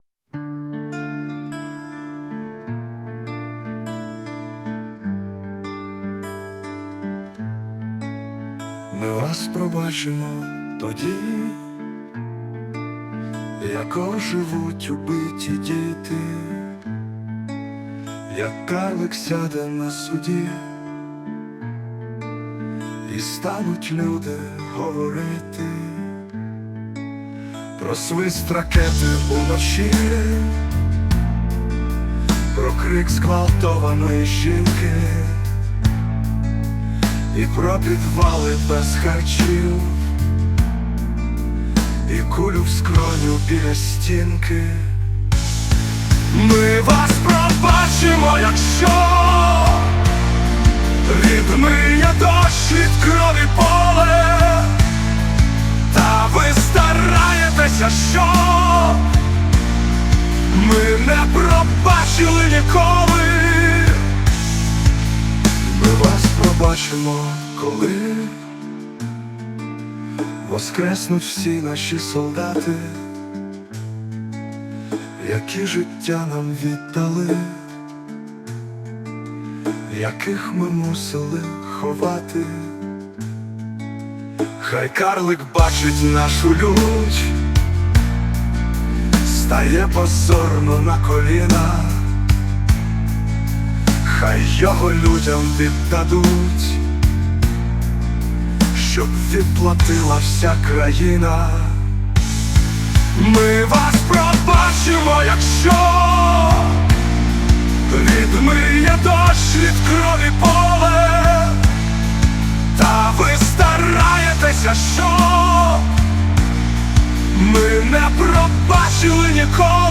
Музика: ШІ.